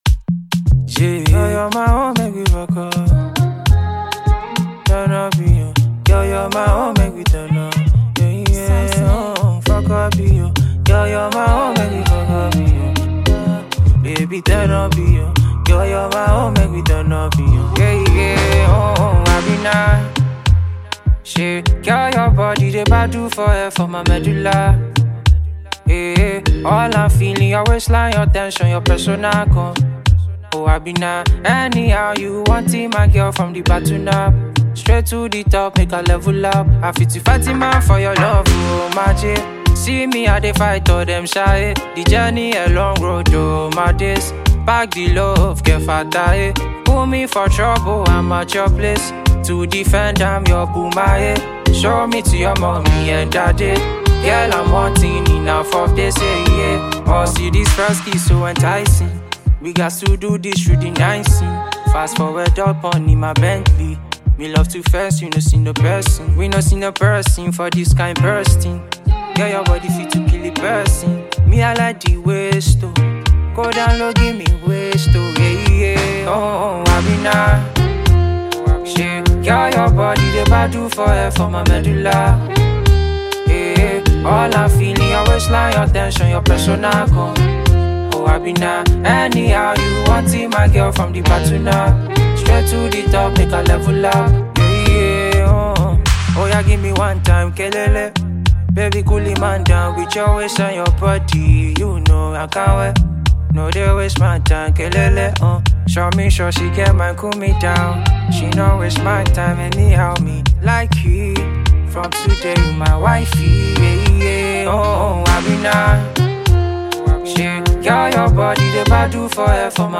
a thrilling new gbedu song